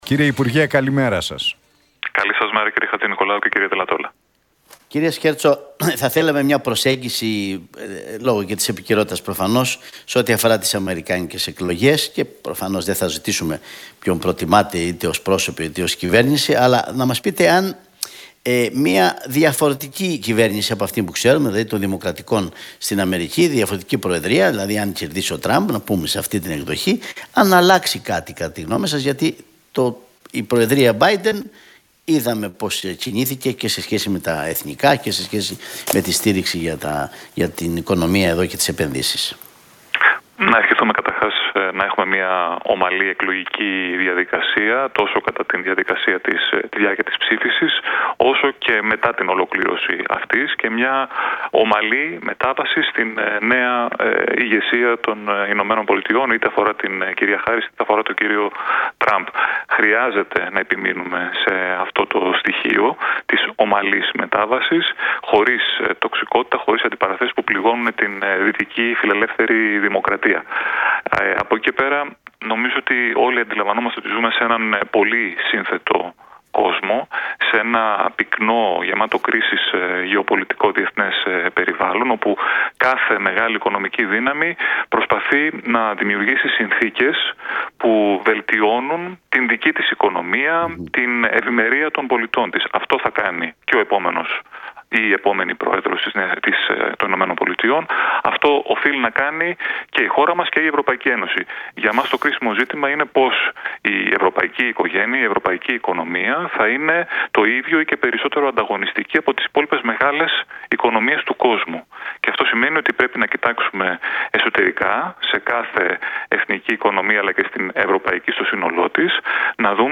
Σκέρτσος στον Realfm 97,8: Δεν υφίσταται κάποια συζήτηση για τον εκλογικό νόμο